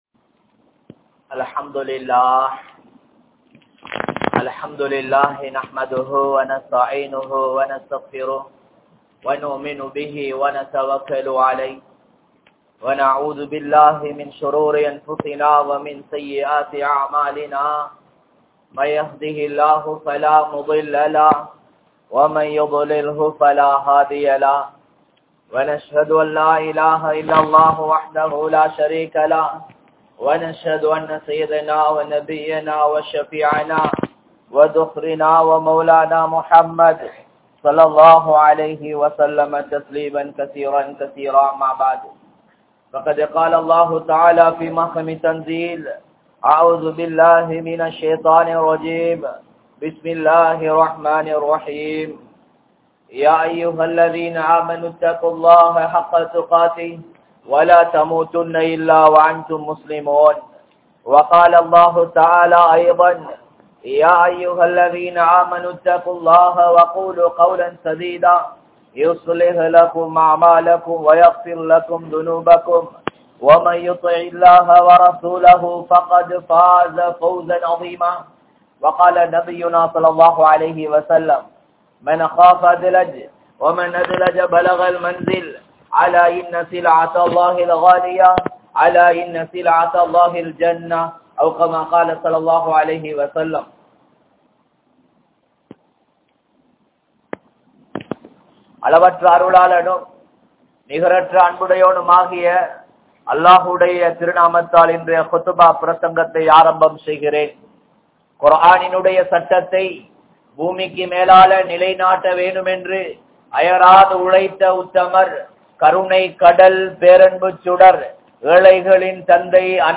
Ahlaaq (நற்பண்புகள்) | Audio Bayans | All Ceylon Muslim Youth Community | Addalaichenai